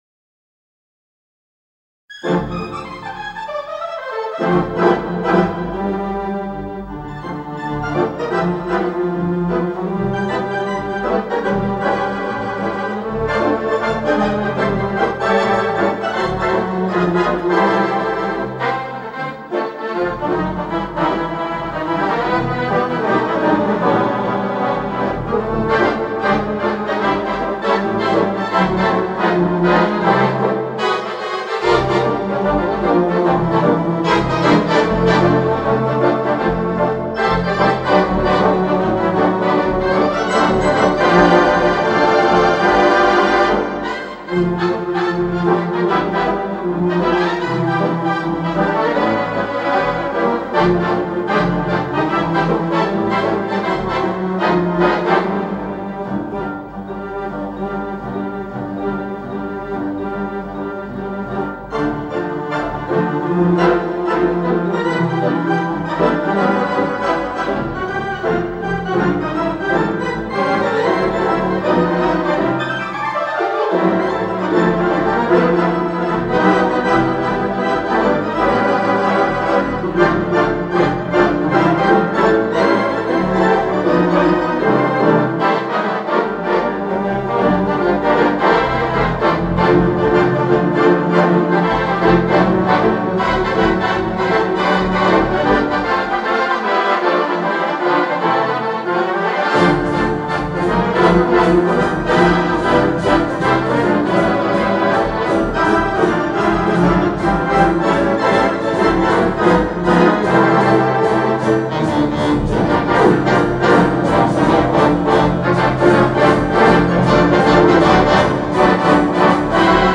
Digital Theatre Organ
The Second Concert